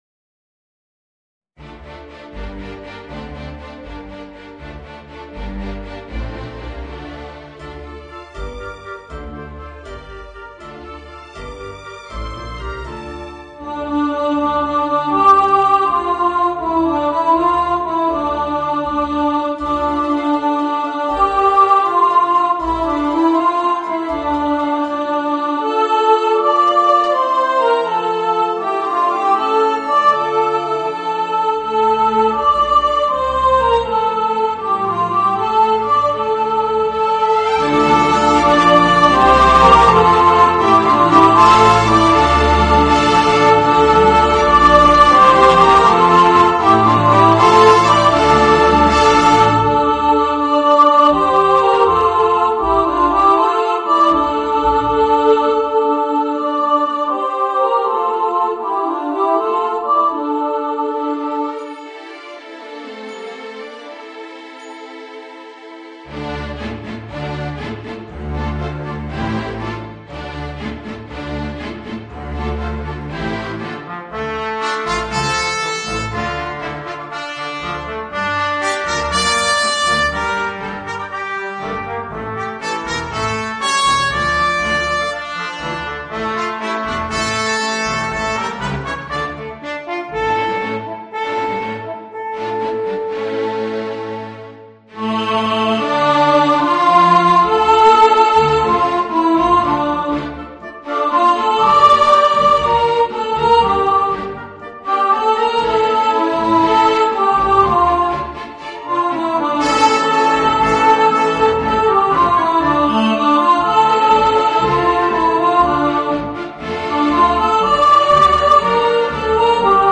Voicing: Children's Choir and Orchestra